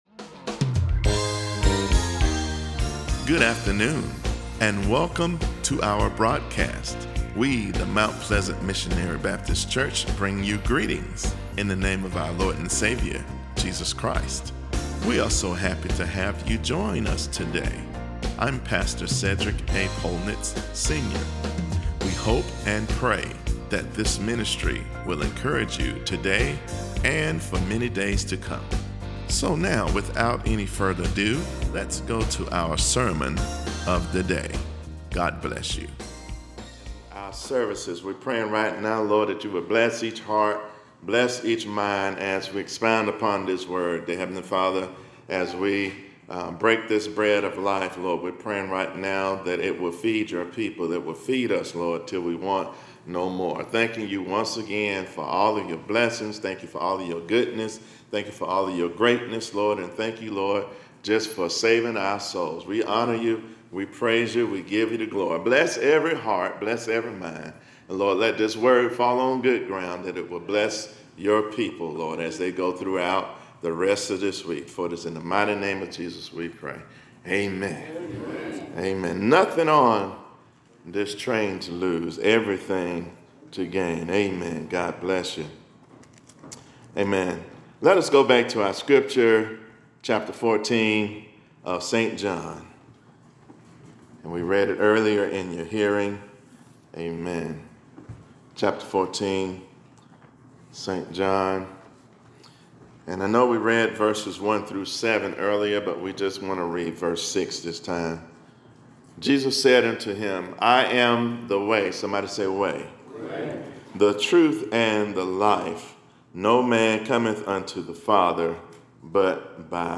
Closing Song